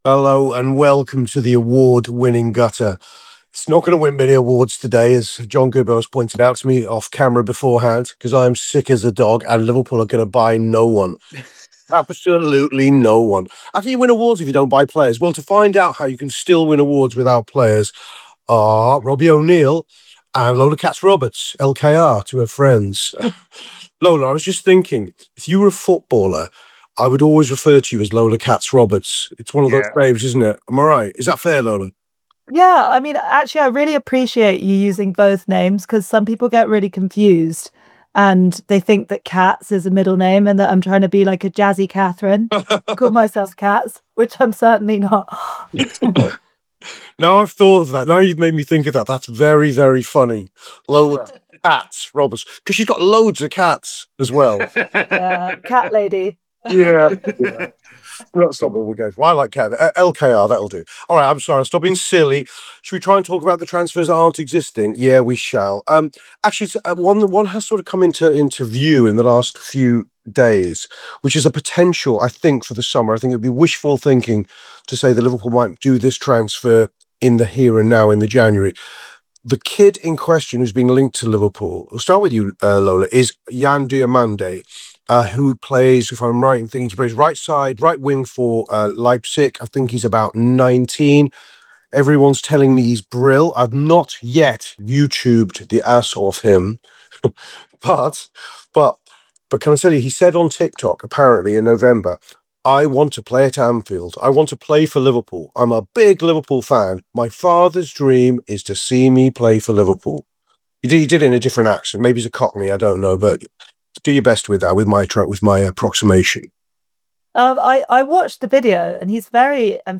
Below is a clip from the show – subscribe for more on the latest Liverpool transfer news…